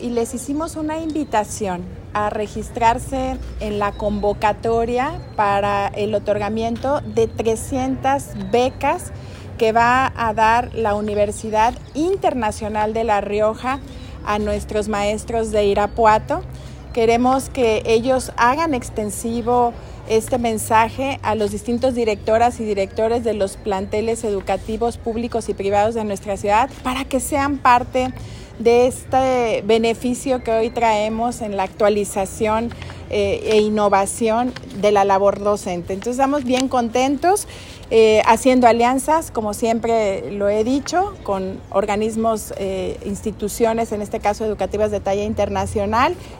Lorena Alfaro García, presidenta municipal